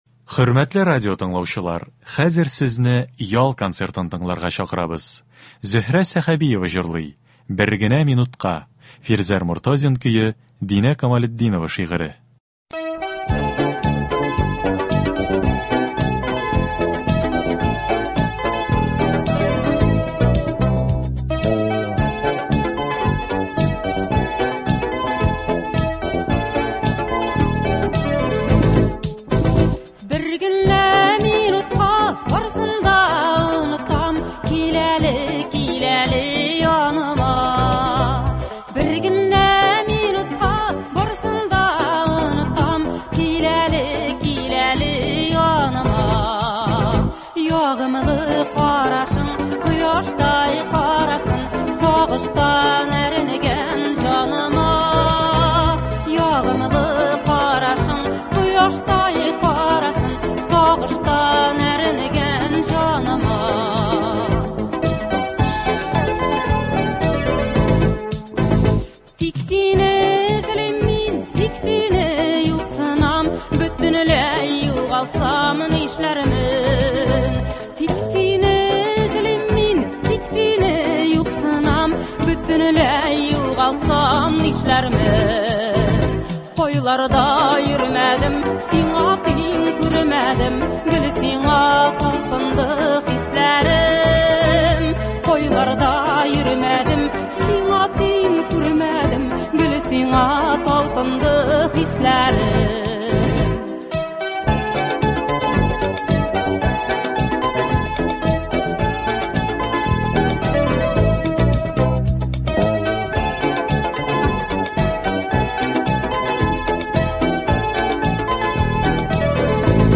Без сезнең өчен, хөрмәтле радиотыңлаучыларыбыз, яхшы кәеф, күңел күтәренкелеге бирә торган концертларыбызны дәвам итәбез.